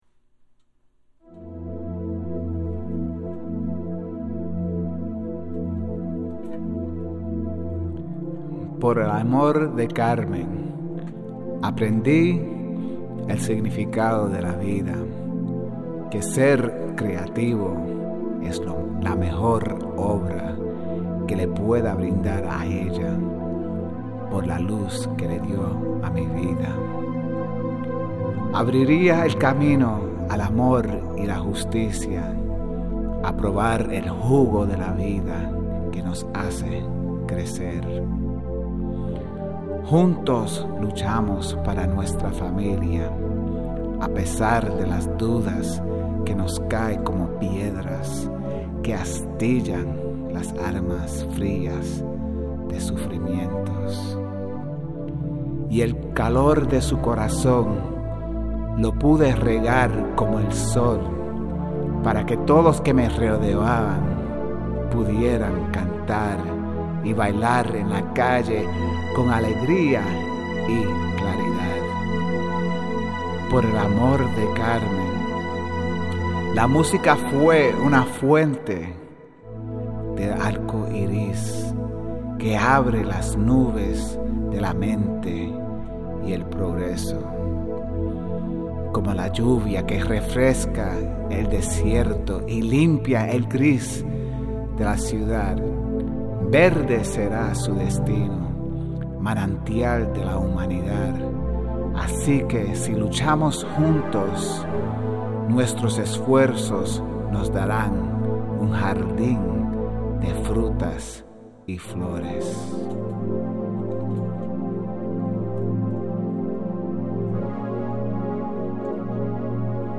my poem, read by yours truly